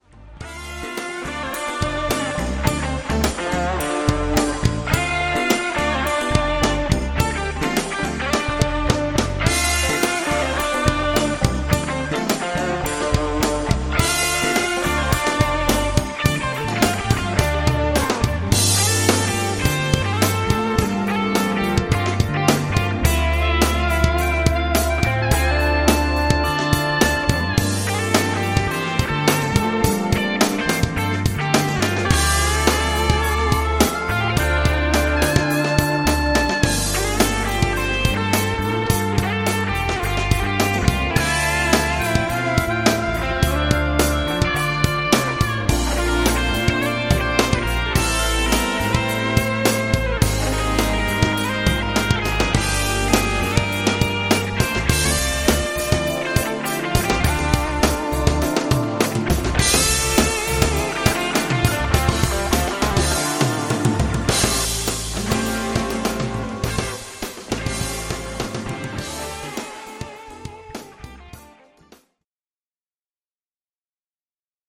guitar, sax, pan flute and harmonica
thumpy funk